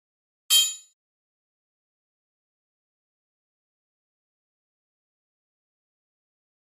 Flash Alarm High Frequency Electronic Chime